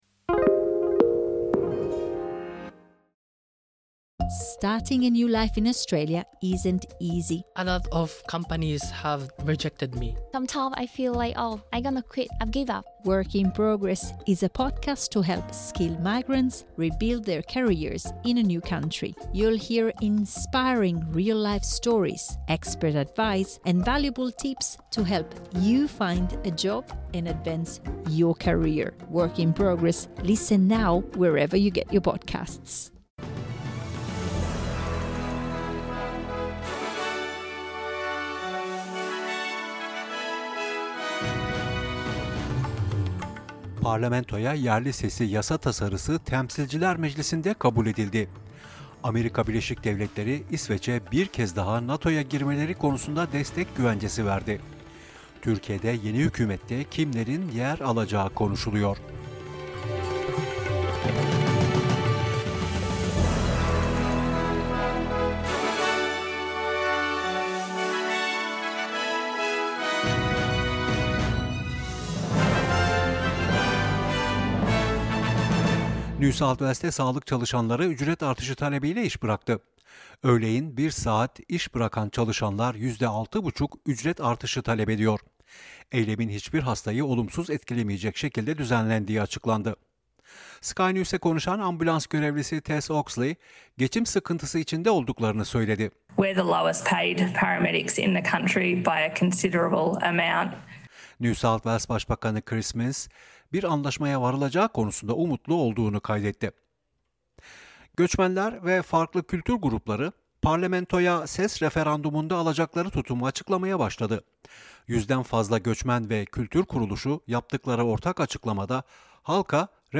SBS Türkçe Haber Bülteni 31 Mayıs